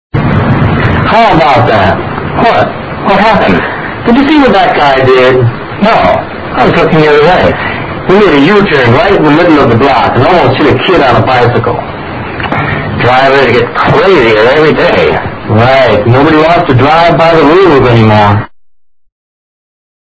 Dialogue 11